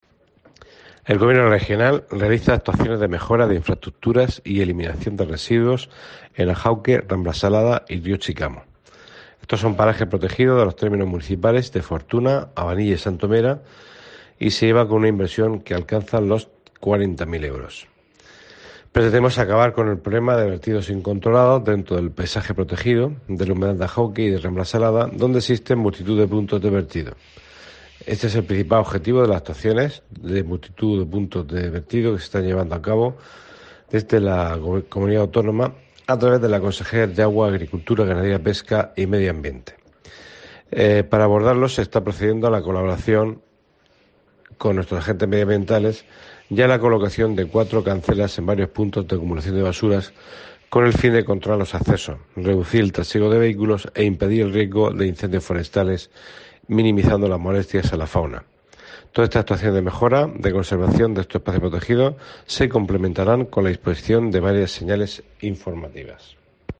Fulgencio Perona, director general del Medio Natural